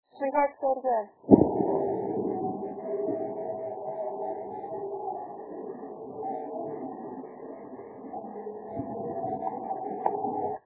Here is an EVP I picked up which reminds me of chanting/singing in the background.
Singing.mp3